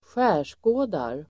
Ladda ner uttalet
skärskåda verb, examine , scrutinize Grammatikkommentar: A & x Uttal: [²sj'ä:r_skå:dar] Böjningar: skärskådade, skärskådat, skärskåda, skär|skådar Synonymer: granska, nagelfara, syna, undersöka Definition: granska noga